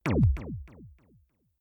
laser1.mp3